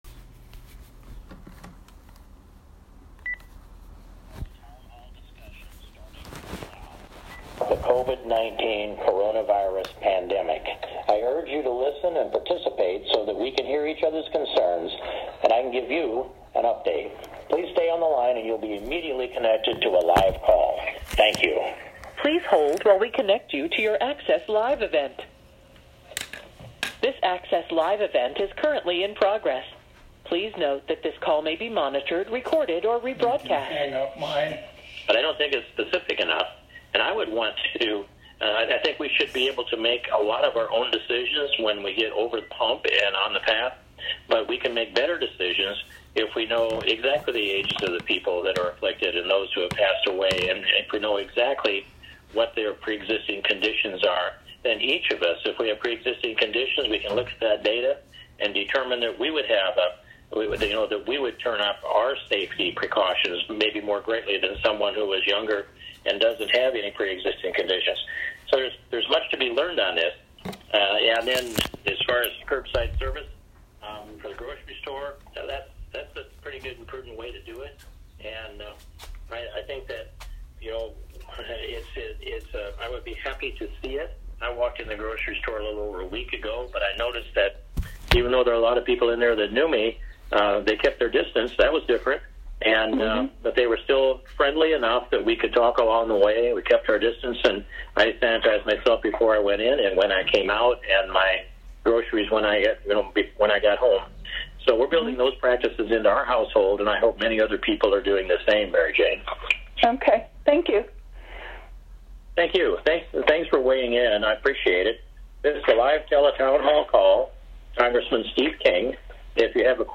An Iowan recorded most of that call (missing the first portion) and p
Steve-King-town-hall-4-7-20.m4a